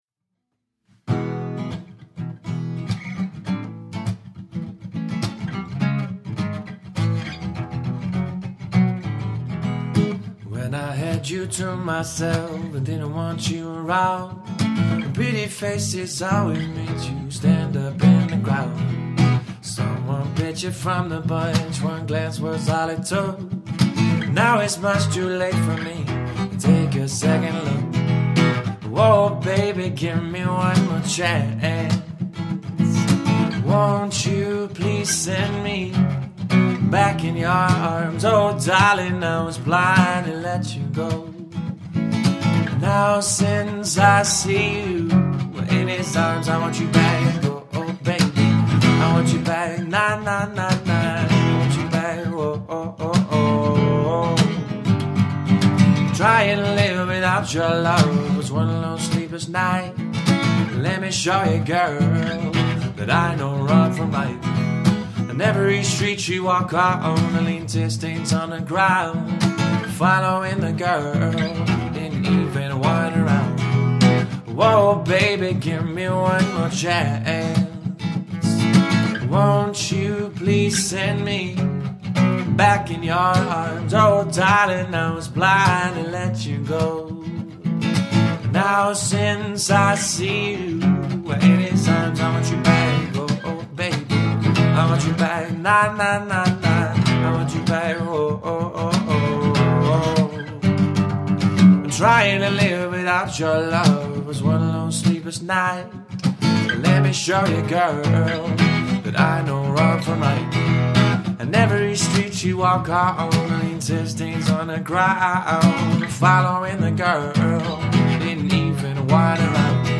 relaxed acoustic lounge style set
Vocals / Acoustic Guitar